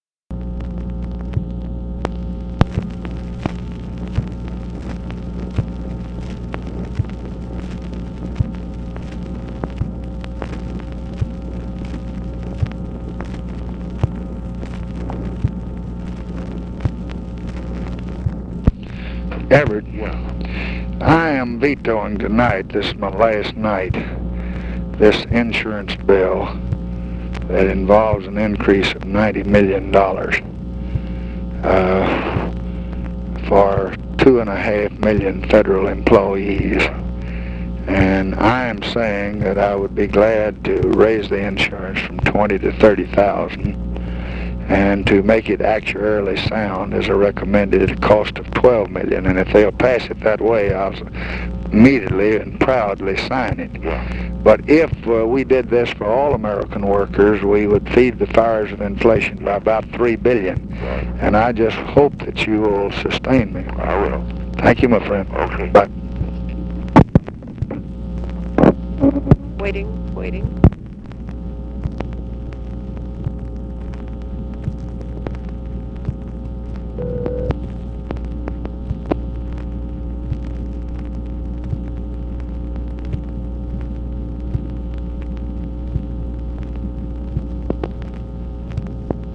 Conversation with EVERETT DIRKSEN, September 12, 1966
Secret White House Tapes